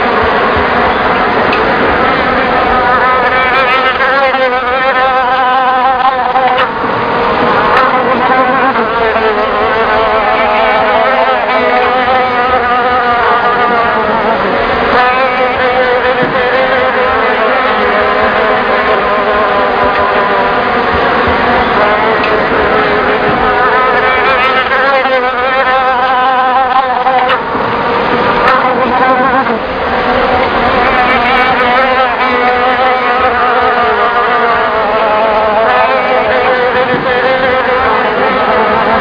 flies.mp3